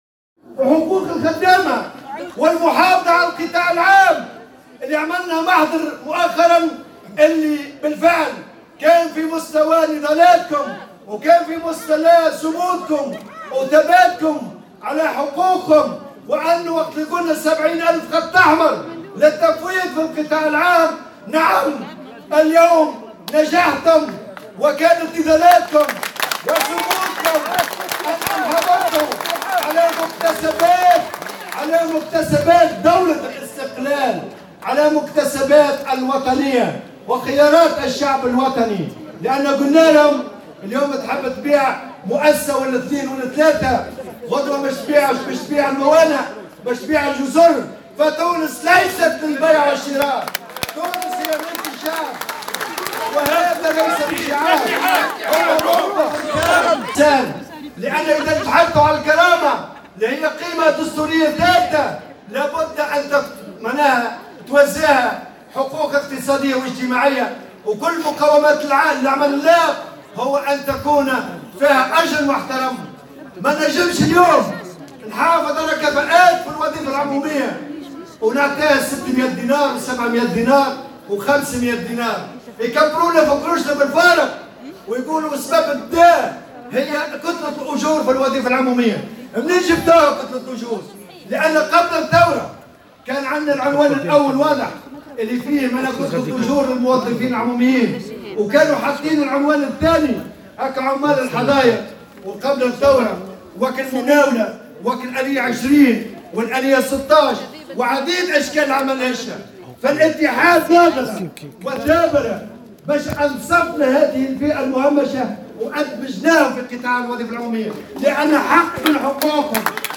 وأضاف قائلا إنه يتم اختيار أشخاص ليس لهم أدنى مستوى من الكفاءة و"يتعلمو في الحجامة في ريوس ليتامى" (يتعلّمون الحلاقة من خلال اليتامى)، وفق تعبيره باللغة الدراجة واستنادا إلى مثل شعبي تونسي في هذا الصدد. وجاء ذلك في كلمة ألقاها خلال تجمع عمالي لأعوان وزارة التجهيز بتونس العاصمة، واكبه مراسل "الجوهرة أف أم"، استعدادا لإضراب عام قرّره الاتحاد بقطاع الوظيفة العمومية يوم 22 نوفمبر 2018.